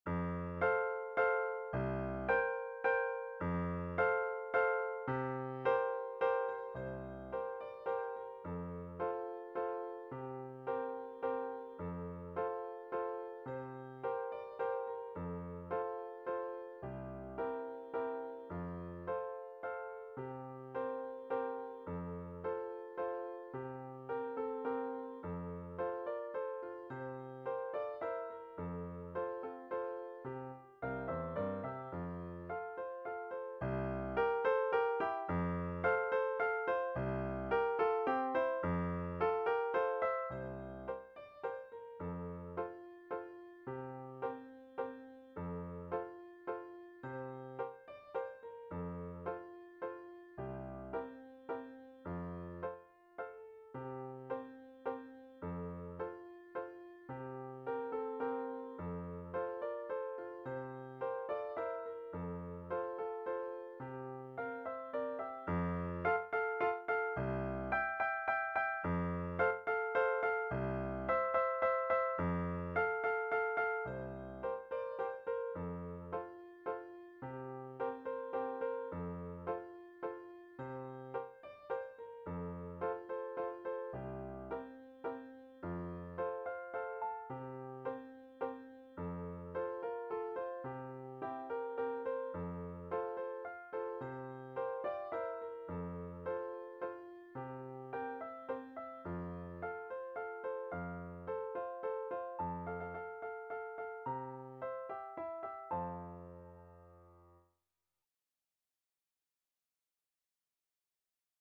More We Get Together Piano Vocal
more-we-get-together-pno-vocal.mp3